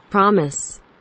promise kelimesinin anlamı, resimli anlatımı ve sesli okunuşu